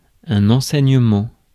Ääntäminen
US : IPA : [ɪn.ˈstrək.ʃən]